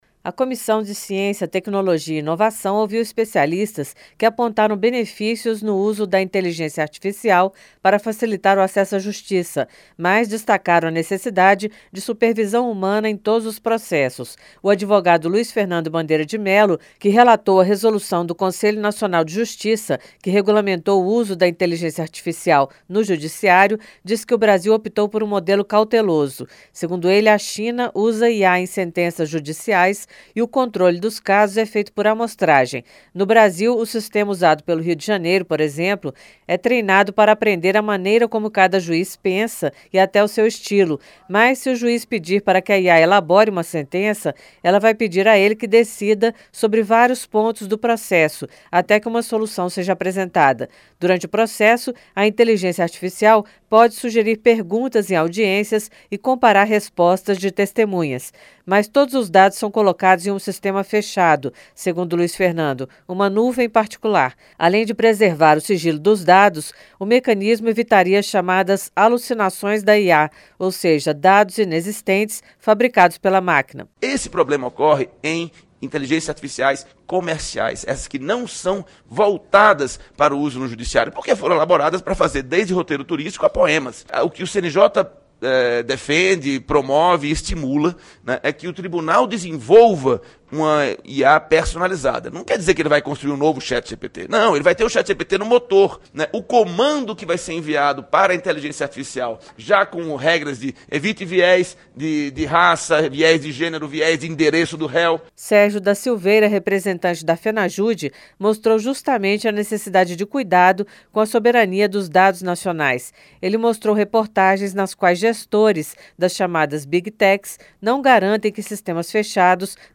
COMISSÃO OUVE ESPECIALISTAS SOBRE USO DA INTELIGÊNCIA ARTIFICIAL NO JUDICIÁRIO. A REPÓRTER